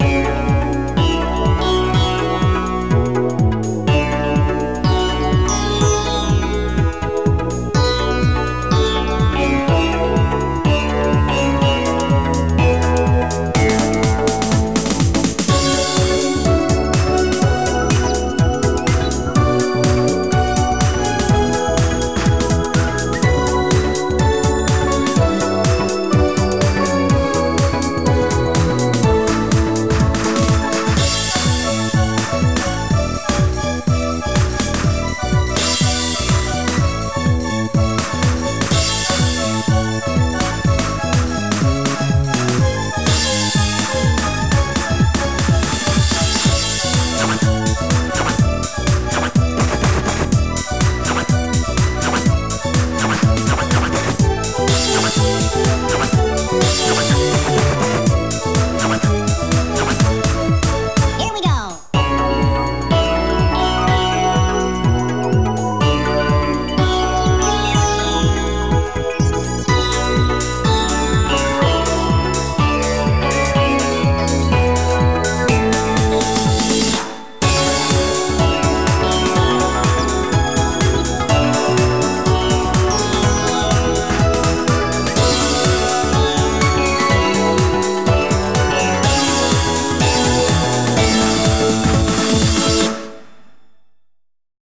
BPM124
Better quality audio.